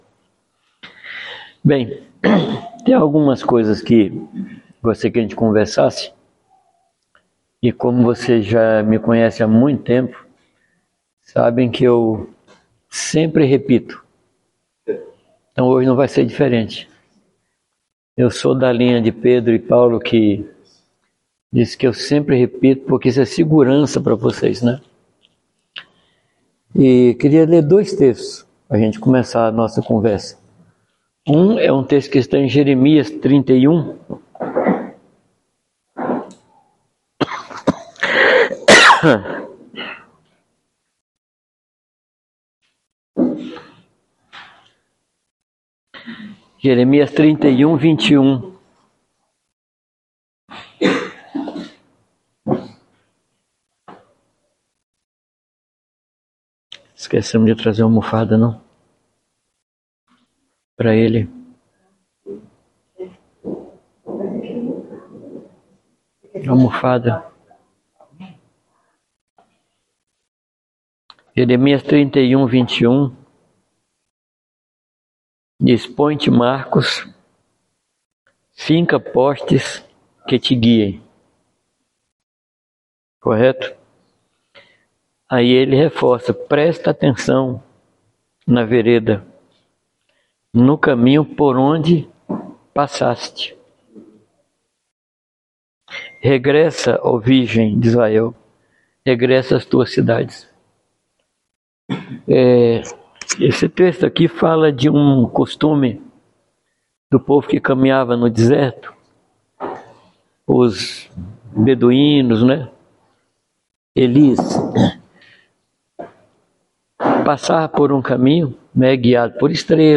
Palavra ministrada
na reunião dos líderes dos grupos menores